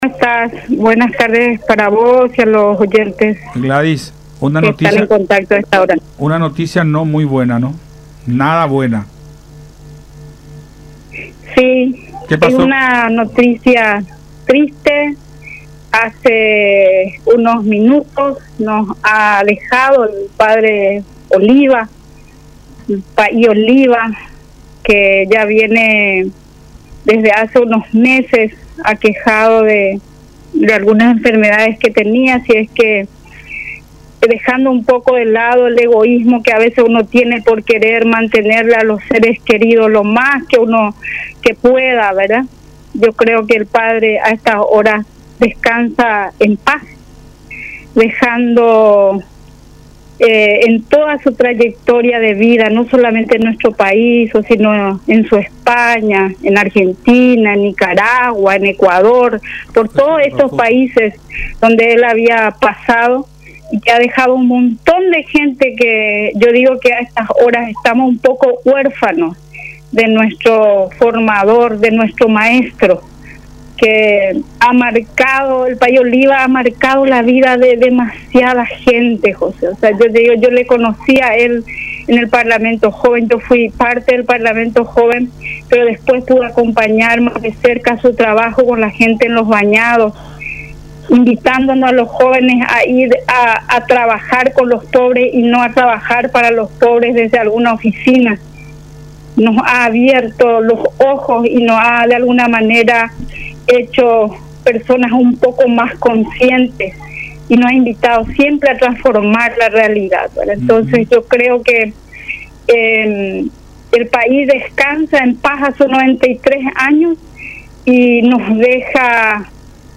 en conversación con Buenas Tardes La Unión.